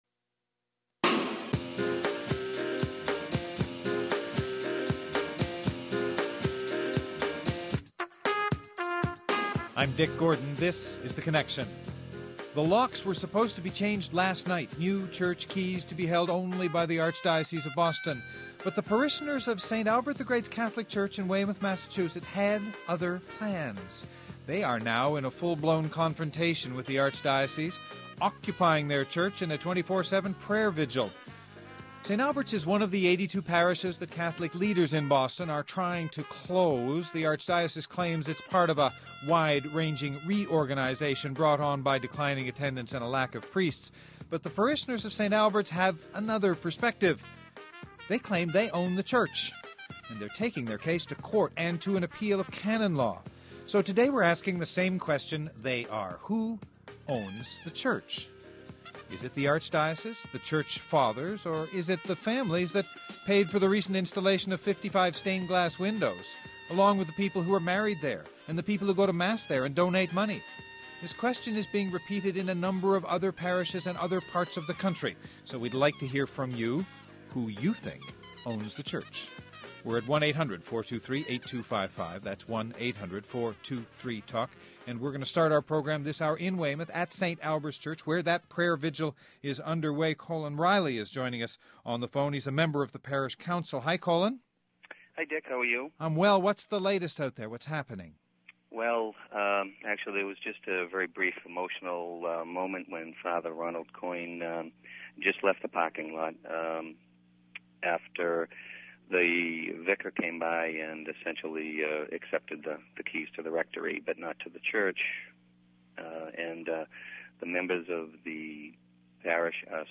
We convene a round table of Republican delegates from Wisconsin to hear why and how they hope to end the reign of the Democrats in “America’s Dairy Land.”